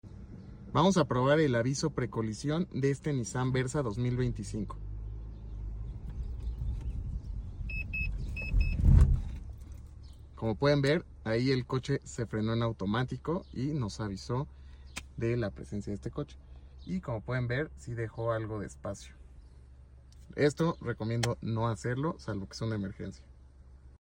Freno de emergencia Nissan Versa